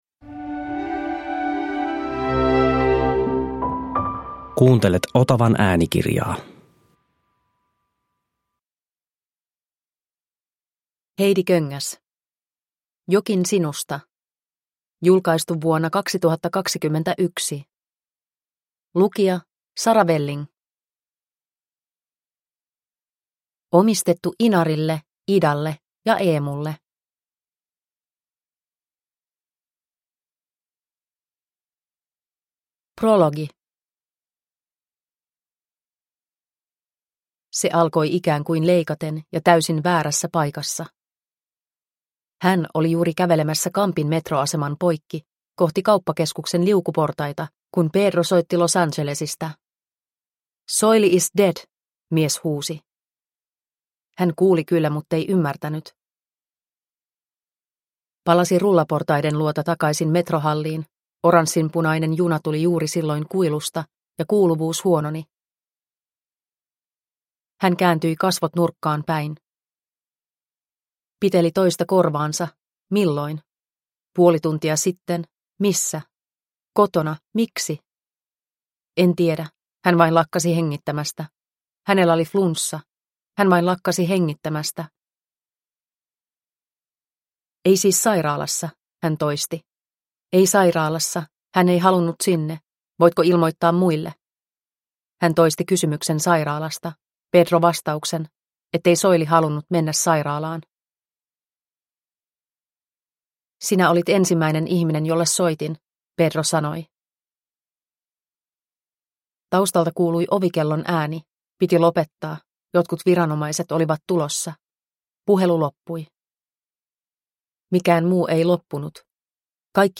Jokin sinusta – Ljudbok – Laddas ner